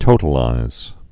(tōtl-īz)